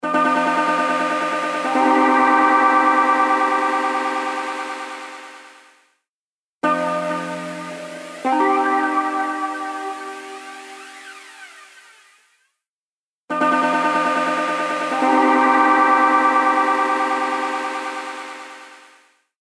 H910 Harmonizer | Electric Guitar | Preset: Interplanetary
H910-Harmonizer-Eventide-Guitar-Interplanetary.mp3